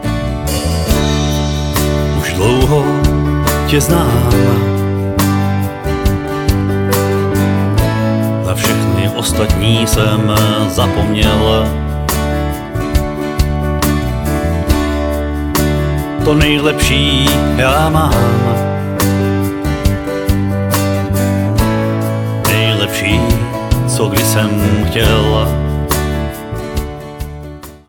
foukací harmonika
klavesy